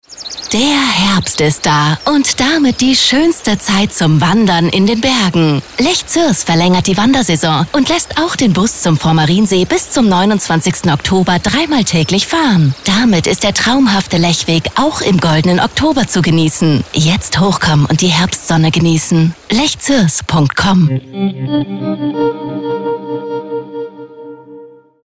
Radiospot Lechweg Herbst 2023.mp3